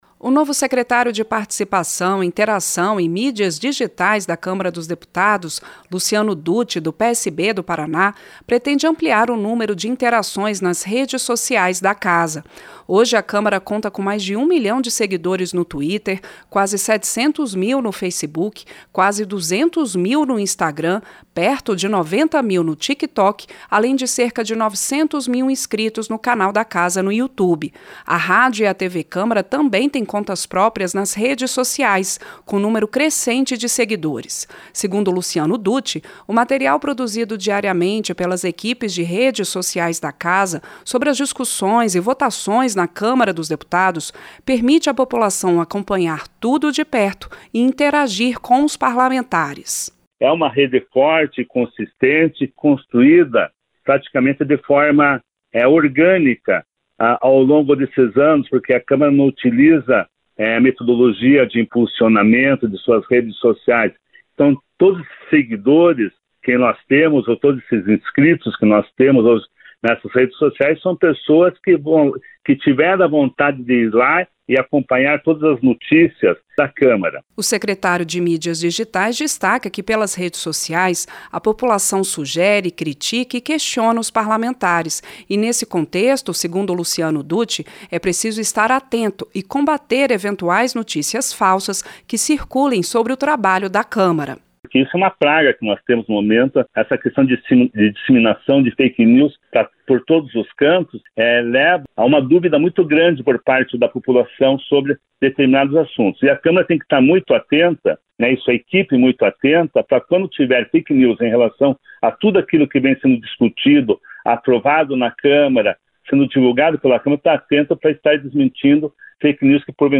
O secretário participou nesta terça-feira (28) do programa “Painel Eletrônico”, da Rádio Câmara.